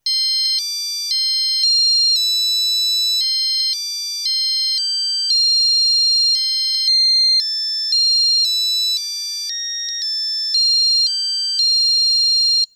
Die Eieruhr “Geburtstags PiepEi” spielt drei verschiedene Melodien für drei Härtegrade.
Sobald der gewünschte Härtegrad erreicht ist, beginnt es zu singen – mit einem Lied passend zum Geburtstag!